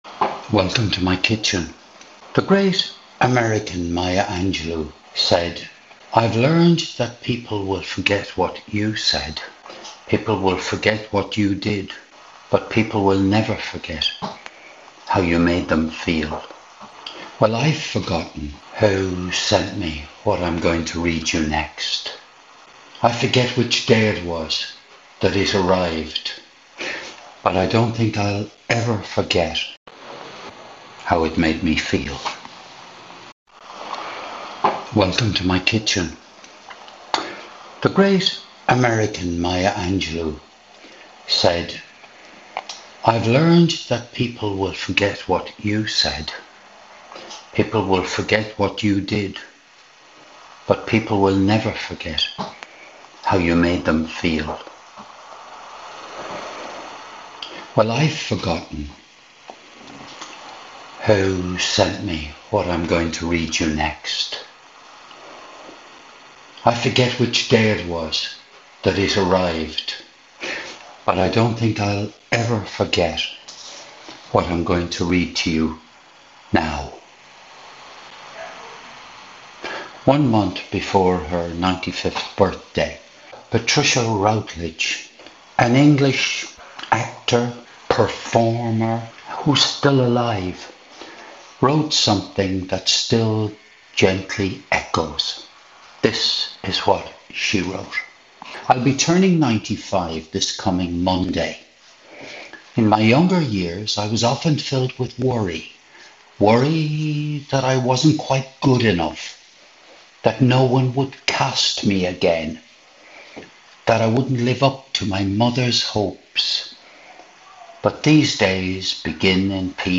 This was recorded in my kitchen on the evening of Monday 28th of July 2025.